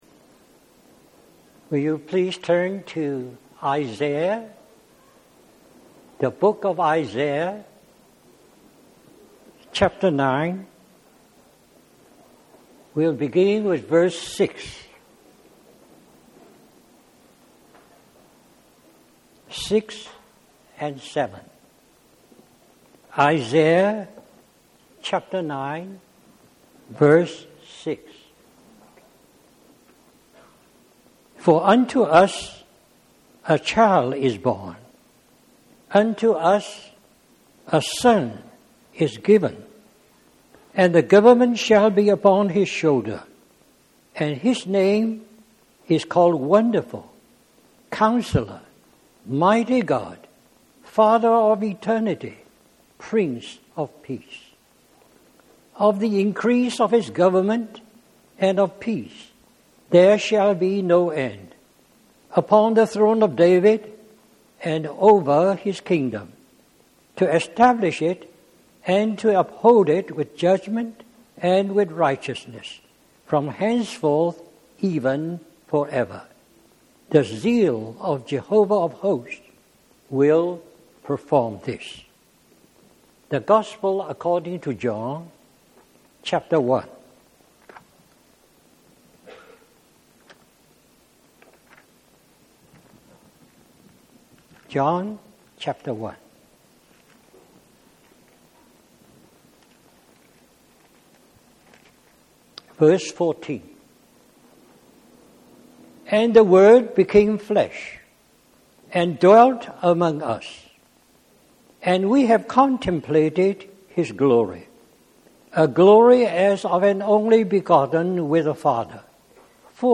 Christian Family Conference